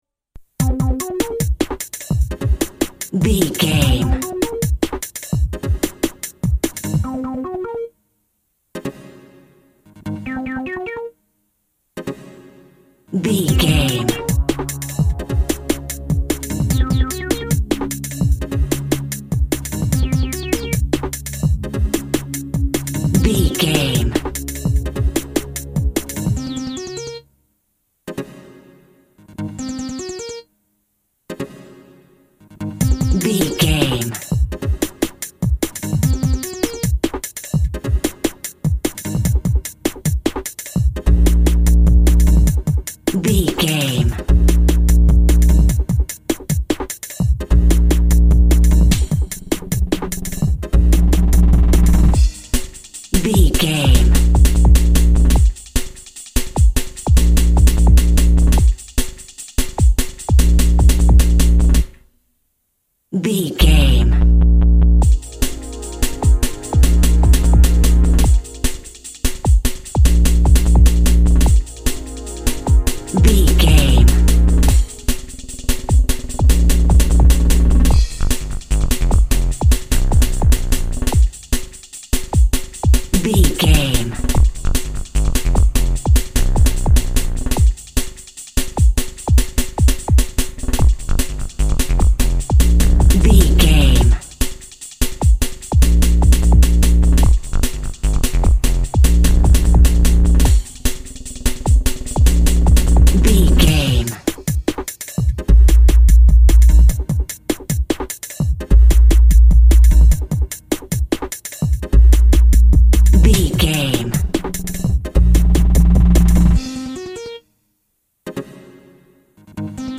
Clubbing Cool Drum & Bass.
Aeolian/Minor
Fast
driving
energetic
futuristic
hypnotic
frantic
drum machine
synthesiser
synth lead
synth bass
electronics